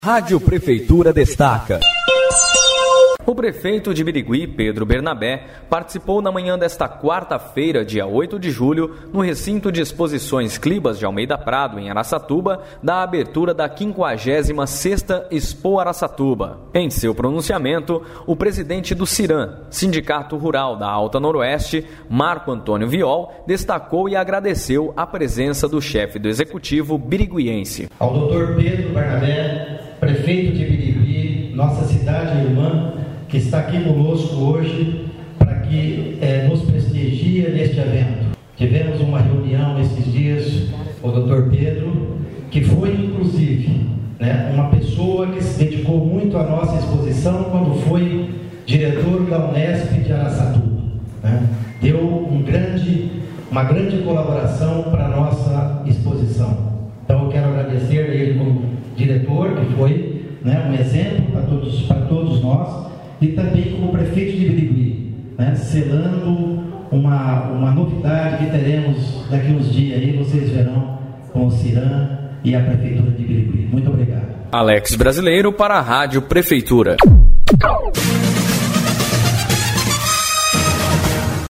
Sonora:
abertura_expoata.mp3